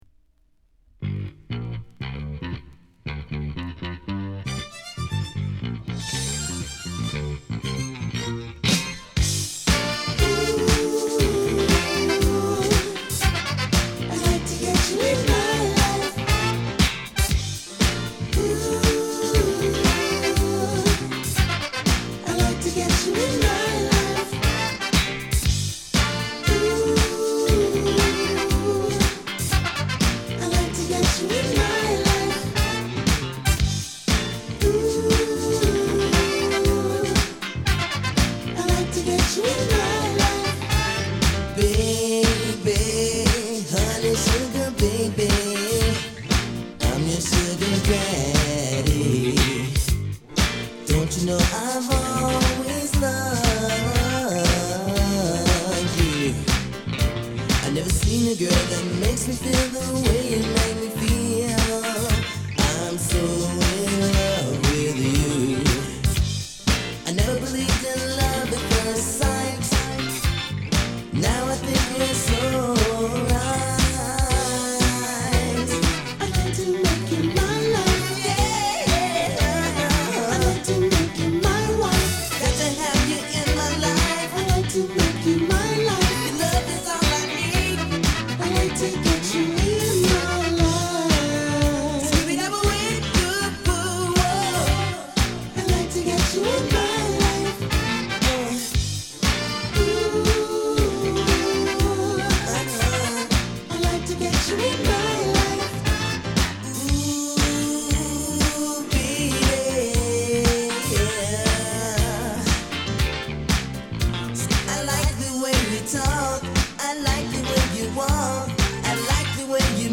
80年代中期の艶やかなディスコ/ソウル/ブギーを収録。